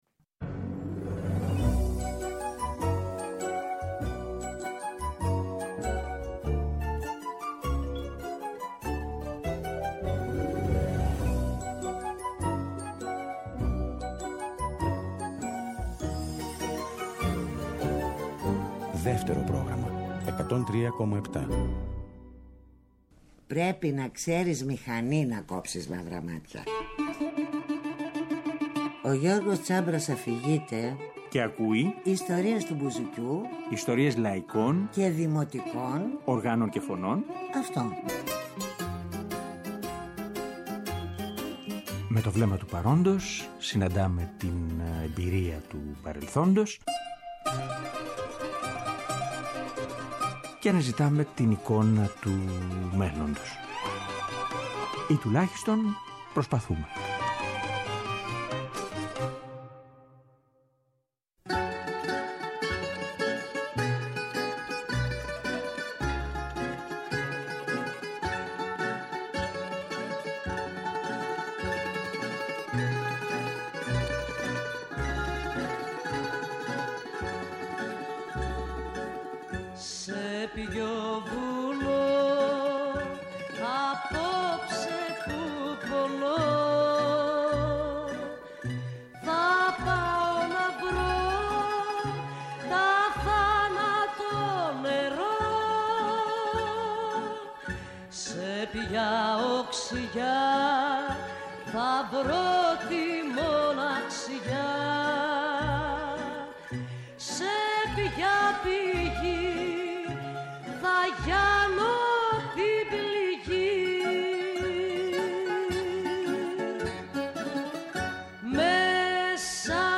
Τρίτη 22 και Τετάρτη 23/11/22, 9 με 10 το βράδυ, στο Δεύτερο Πρόγραμμα.
Και διαβάζουμε αποσπάσματα από αφηγήσεις του για την εποχή και τα τραγούδια του.